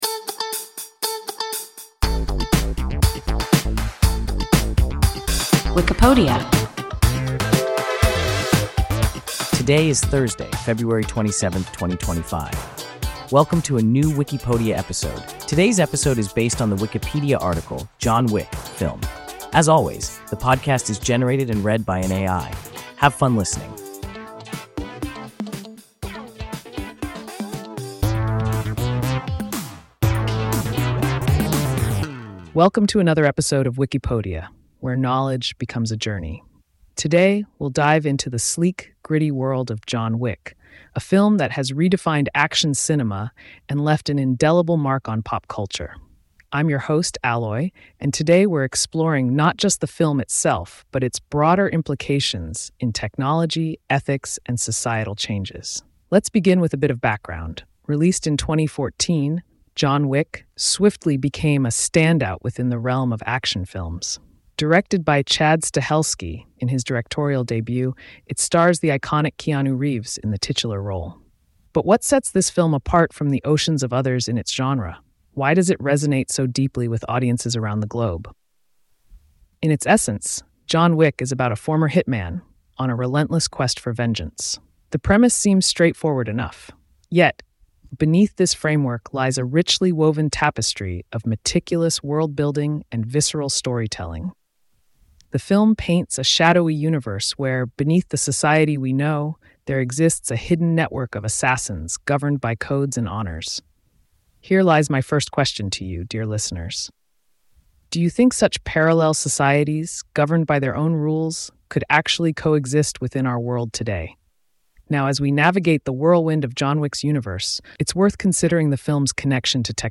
John Wick (film) – WIKIPODIA – ein KI Podcast